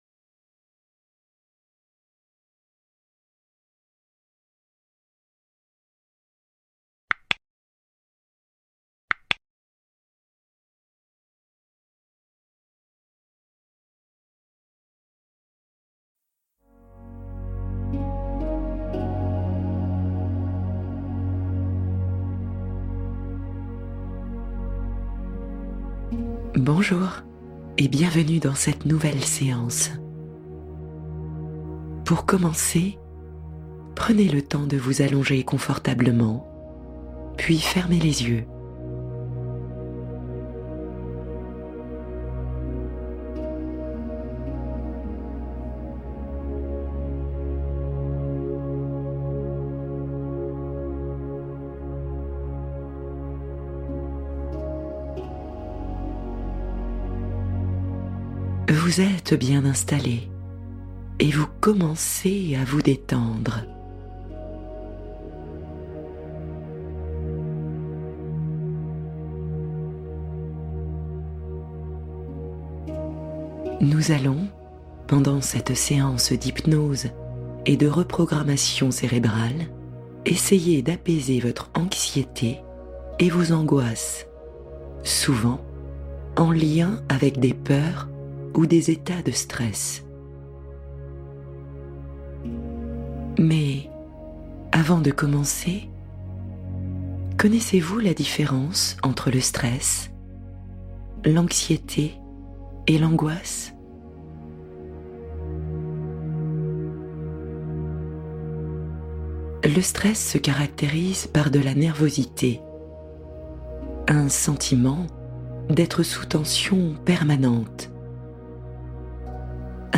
Neutraliser l'Anxiété : Hypnose pour un apaisement mental complet